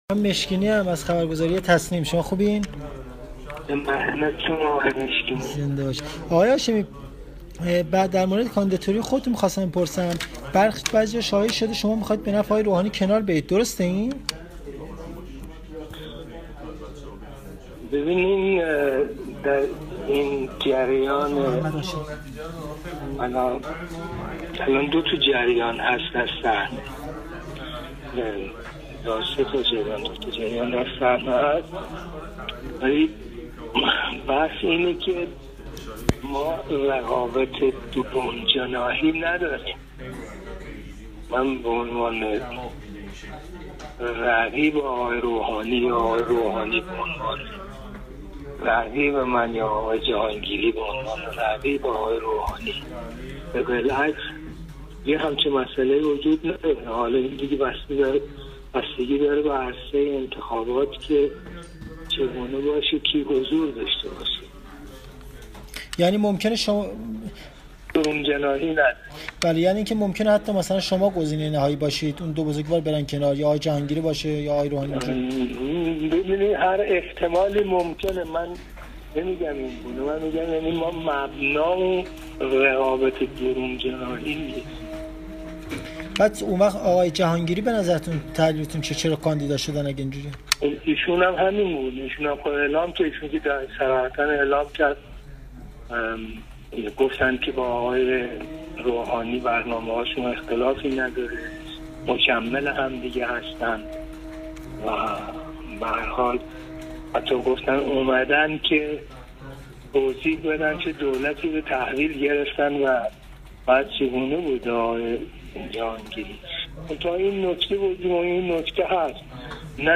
به گزارش خبرنگار سیاسی خبرگزاری تسنیم، محمد هاشمی رفسنجانی در گفت‌وگو با خبرنگار سیاسی خبرگزاری تسنیم در پاسخ به این سؤال که آیا این احتمال وجود دارد که شما در صحنه رقابت بمانید و آقای روحانی به نفع شما کناره گیری کند، گفته هر احتمالی ممکن است.